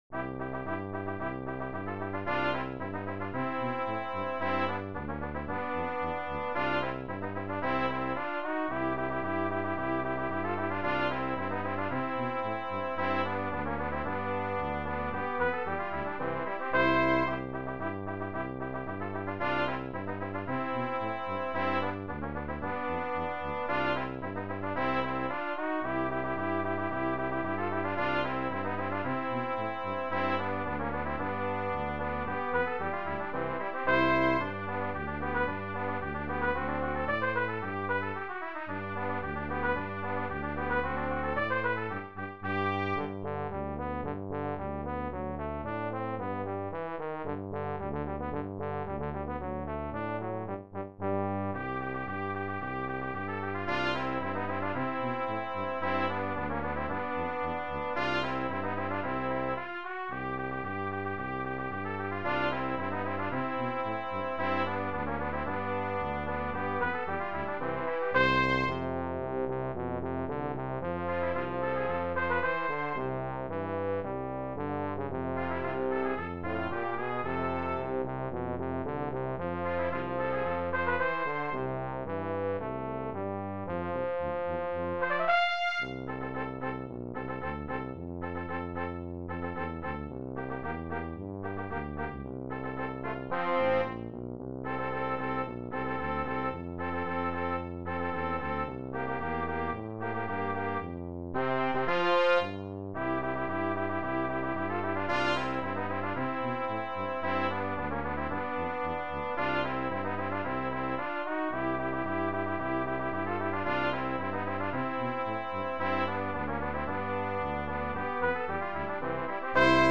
Brass Trio TTT
Traditional